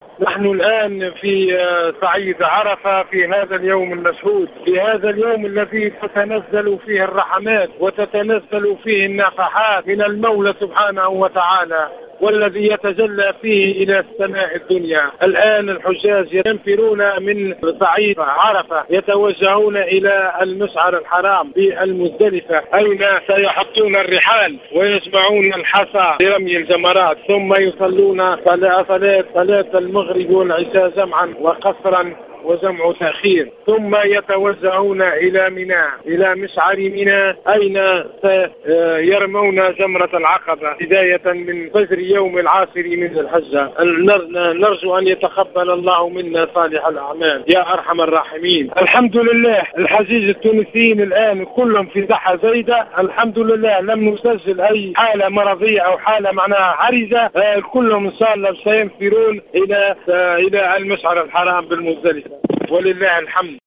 تصريح لـ"الجوهرة أف أم"